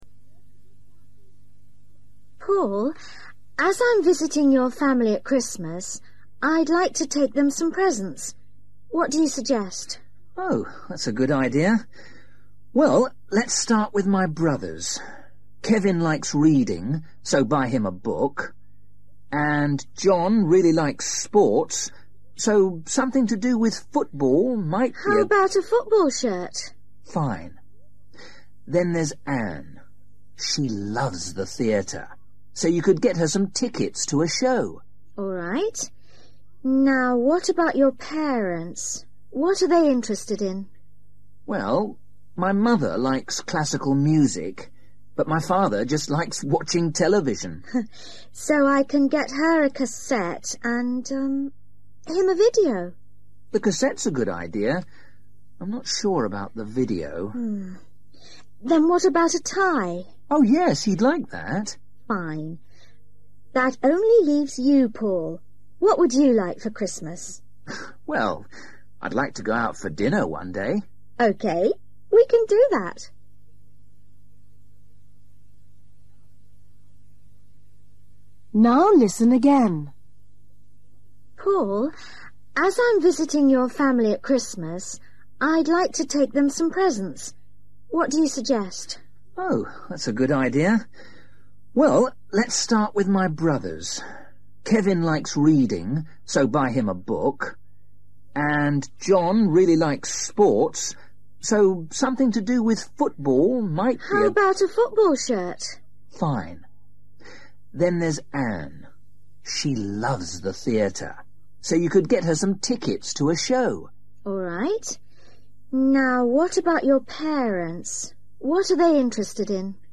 Listen to Teresa talking to Paul about presents for his family. What is she going to buy for each person?